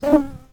Added a hurt sound for the wasp